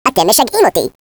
Add hebrew voices